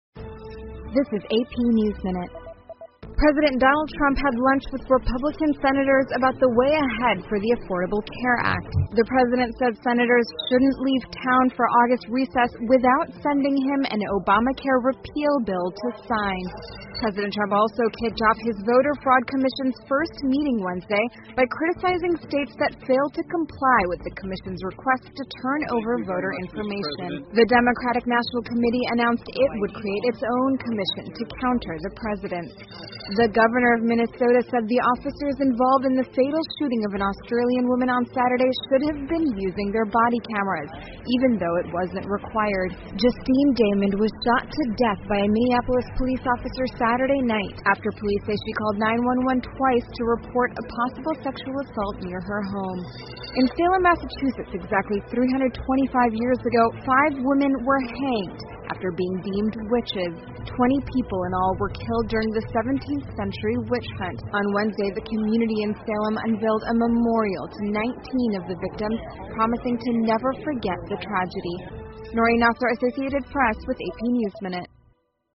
美联社新闻一分钟 AP 2017-07-21 听力文件下载—在线英语听力室